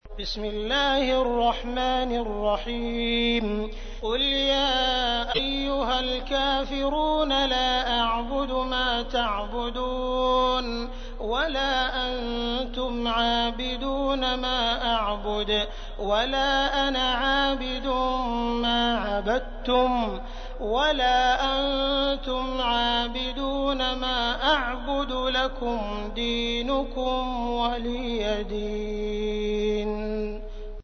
تحميل : 109. سورة الكافرون / القارئ عبد الرحمن السديس / القرآن الكريم / موقع يا حسين